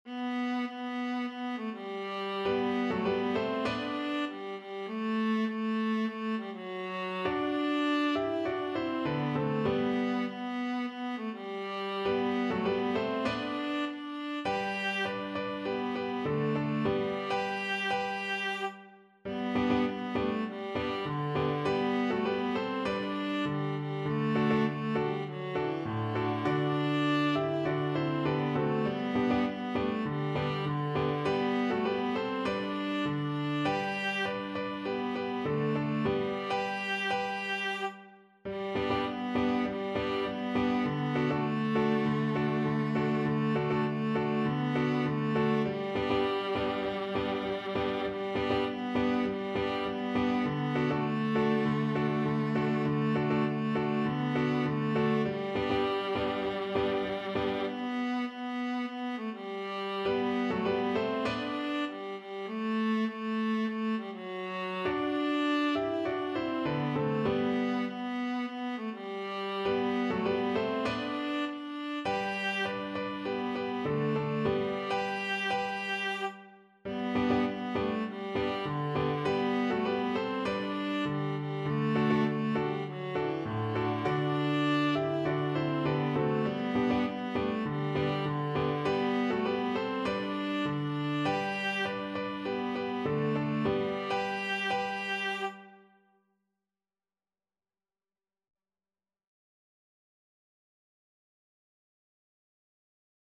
Viola
G major (Sounding Pitch) (View more G major Music for Viola )
4/4 (View more 4/4 Music)
Moderato
Traditional (View more Traditional Viola Music)
Caribbean Music for Viola